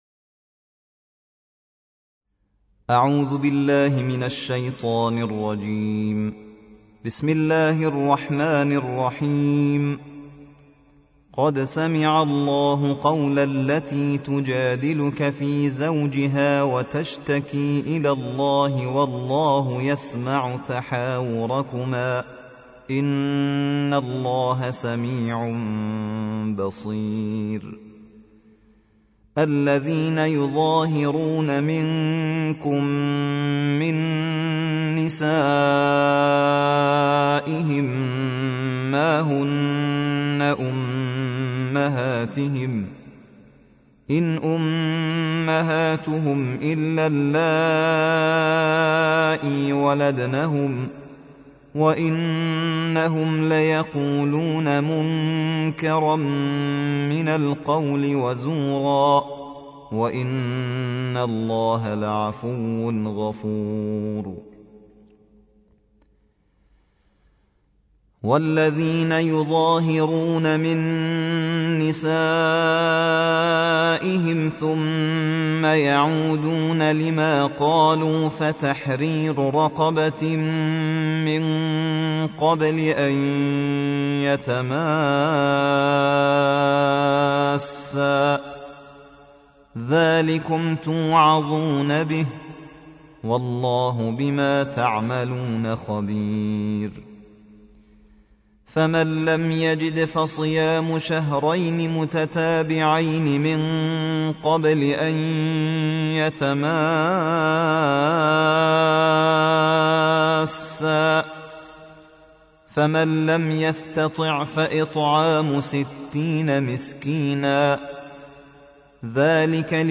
الجزء الثامن والعشرون / القارئ